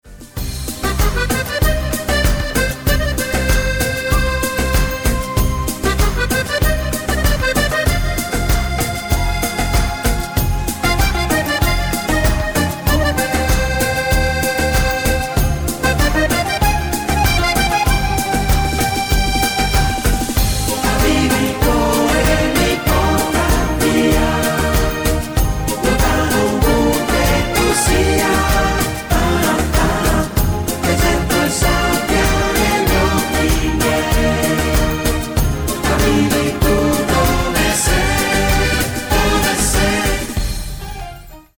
MODERATO  (4.09)